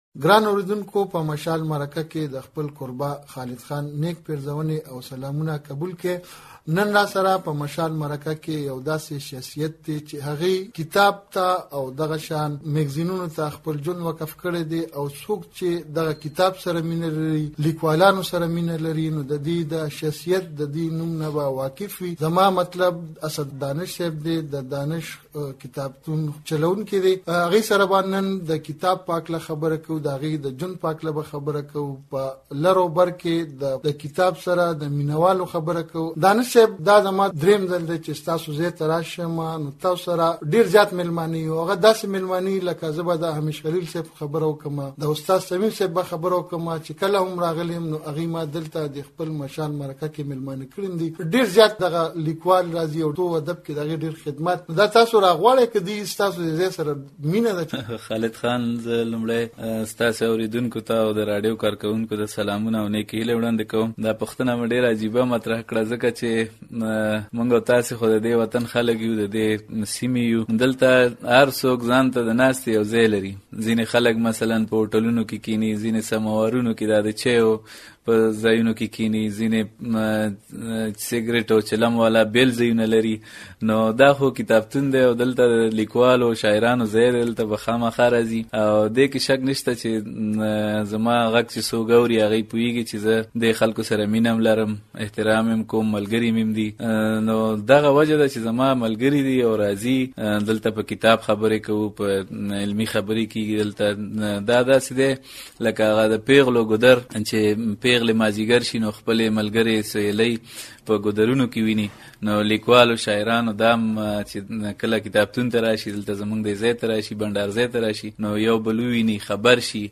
مشال مرکه